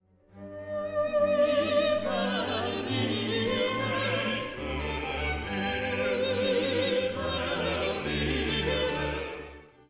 The chorus begin the “Agnus Dei” with a slow, folklike hymn in triple time.
Miserere” on two separate occasions, but the soaring sound of the soprano above the others gives us one more taste of the “Gospel” sound.